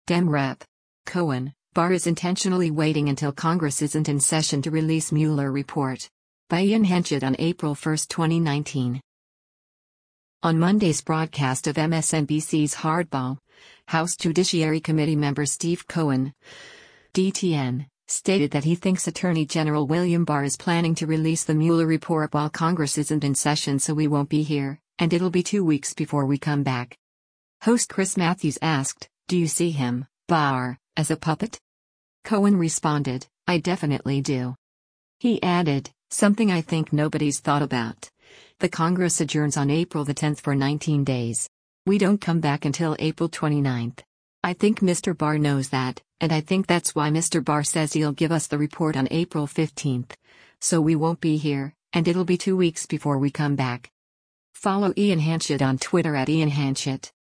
On Monday’s broadcast of MSNBC’s “Hardball,” House Judiciary Committee member Steve Cohen (D-TN) stated that he thinks Attorney General William Barr is planning to release the Mueller report while Congress isn’t in session “so we won’t be here, and it’ll be two weeks before we come back.”
Host Chris Matthews asked, “Do you see him [Barr] as a puppet?”